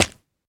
Minecraft Version Minecraft Version latest Latest Release | Latest Snapshot latest / assets / minecraft / sounds / mob / turtle / egg / jump_egg1.ogg Compare With Compare With Latest Release | Latest Snapshot
jump_egg1.ogg